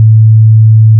**🔊 SFX PLACEHOLDERS (23 WAV - 1.5MB):**
**⚠  NOTE:** Music/SFX are PLACEHOLDERS (simple tones)
zombie_death.wav